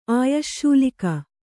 ♪ āyaśśulika